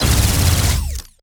GUNAuto_Plasmid Machinegun C Burst Unstable_05_SFRMS_SCIWPNS.wav